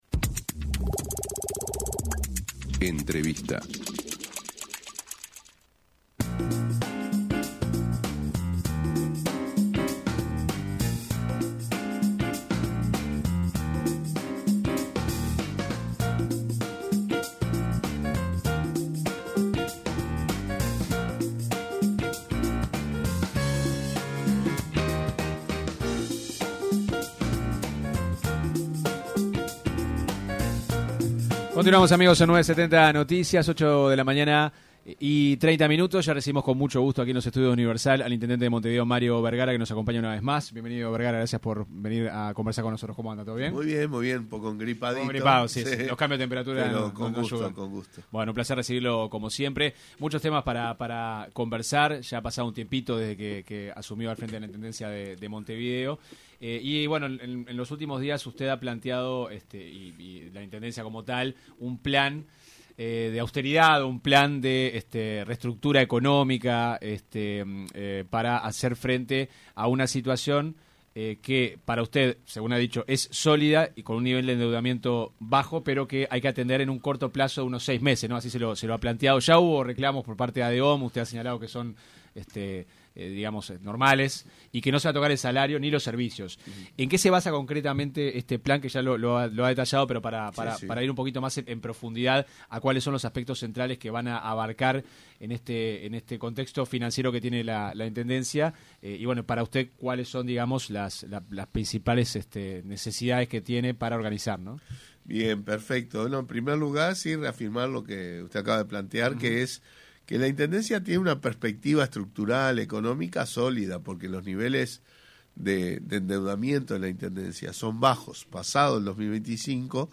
El intendente de Montevideo, Mario Bergara se refirió en diálogo con 970 Noticias, al «ahogamiento financiero» que se denuncia desde filas frenteamplistas por parte del gobierno nacional anterior a la gestión de Carolina Cosse.